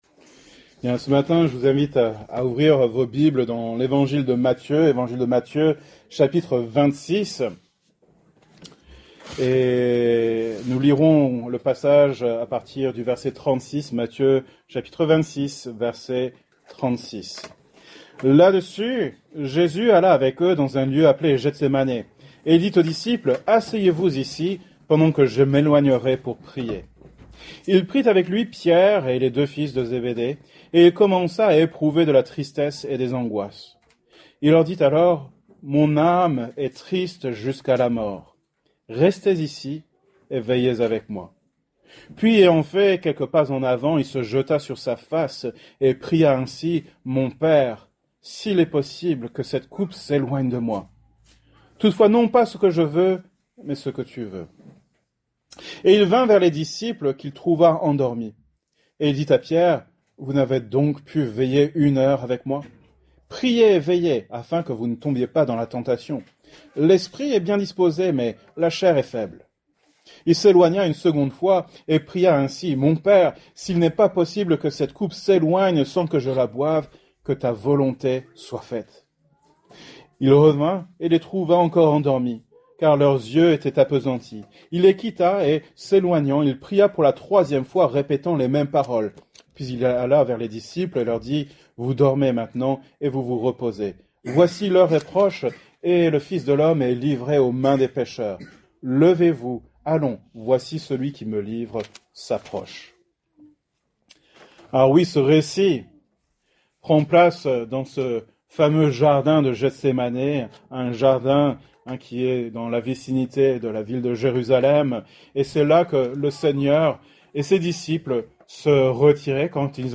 Genre: Prédication